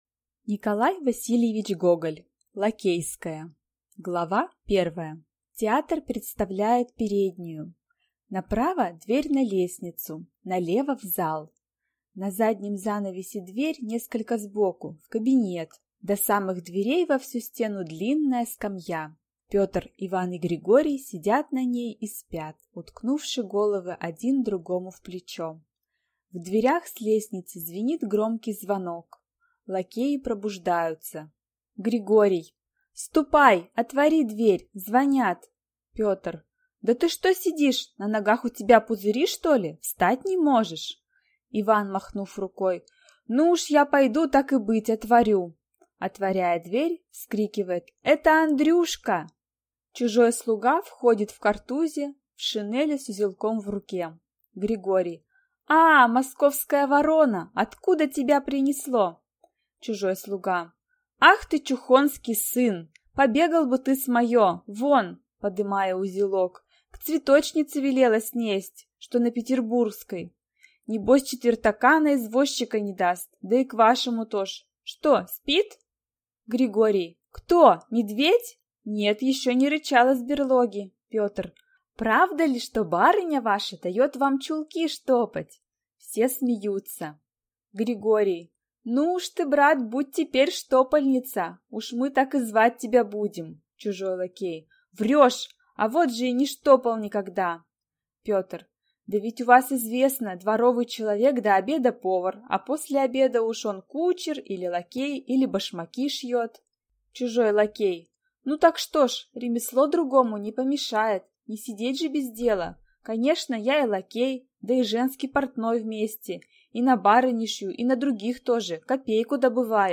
Аудиокнига Лакейская | Библиотека аудиокниг